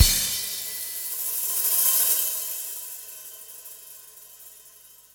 17 rhdrm93cym.wav